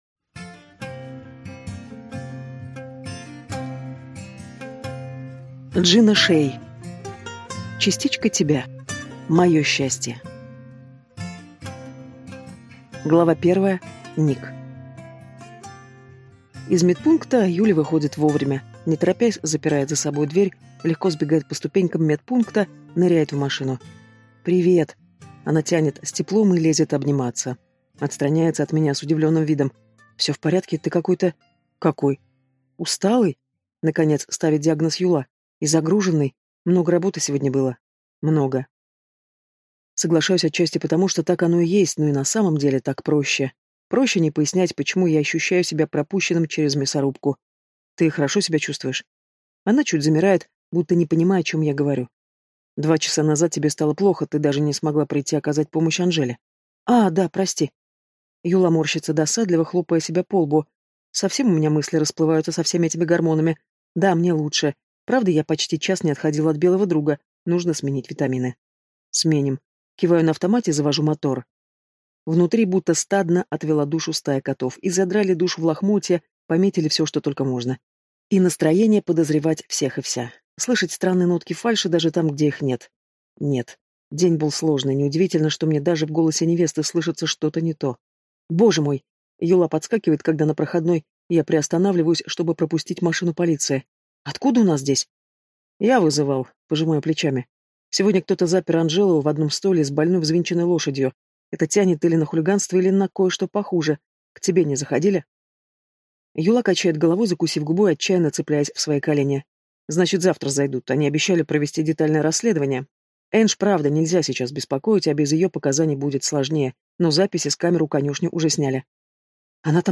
Аудиокнига Частичка тебя. Мое счастье | Библиотека аудиокниг
Прослушать и бесплатно скачать фрагмент аудиокниги